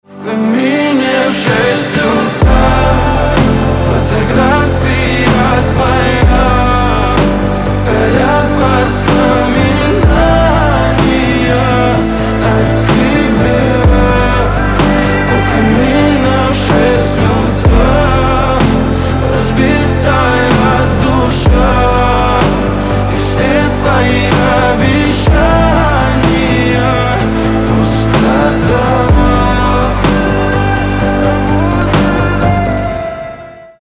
RnB
Поп